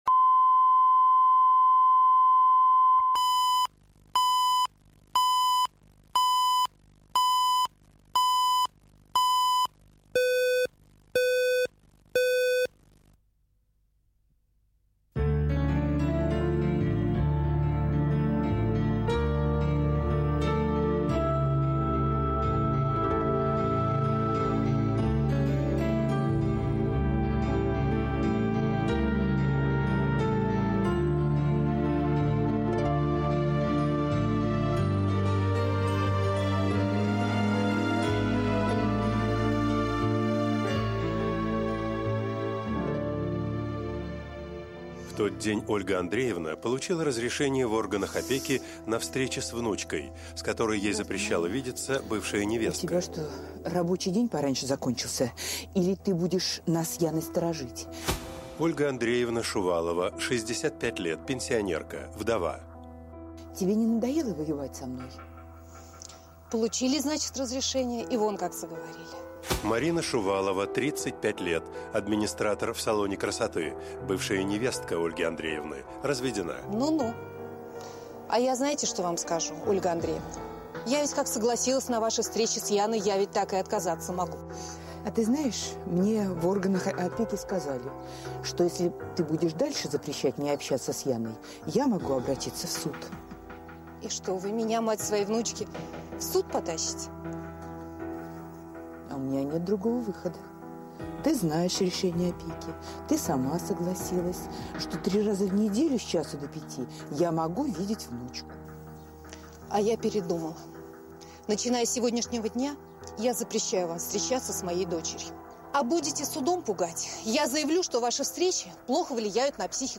Аудиокнига В унисон